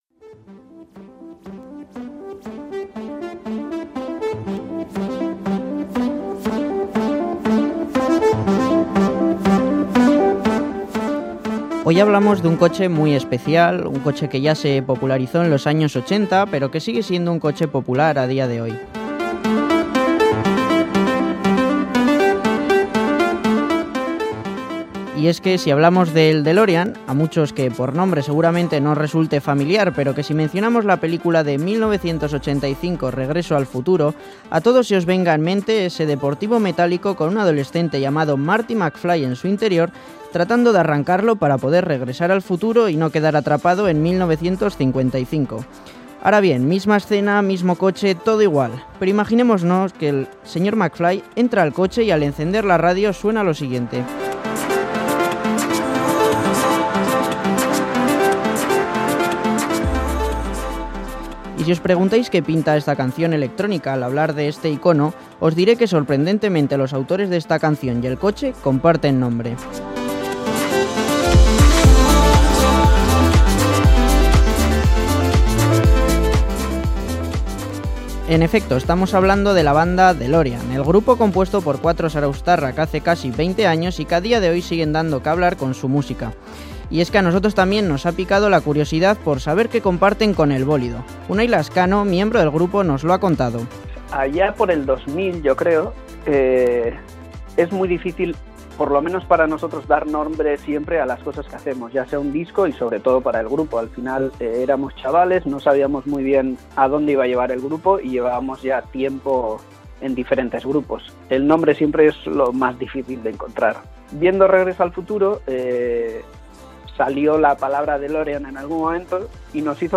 Audio: Reportaje Delorean